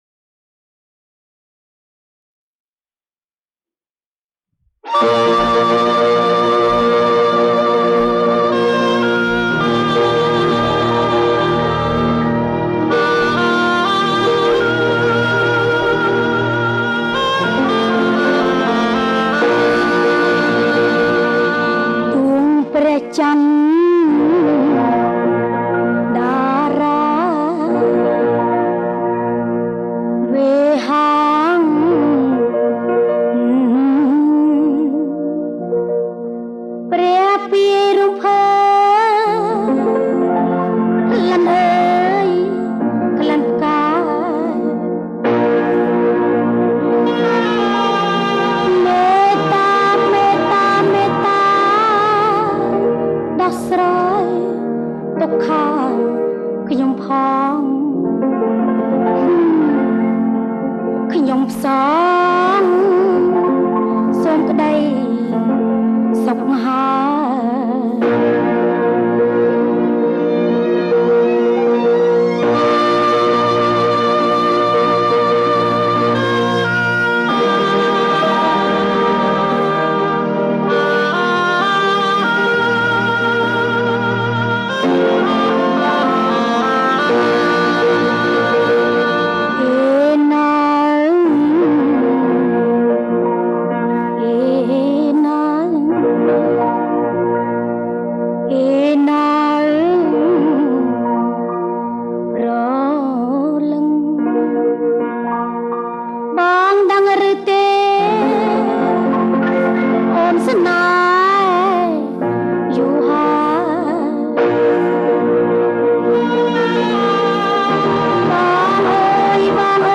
ប្រគំជាចង្វាក់ Rumba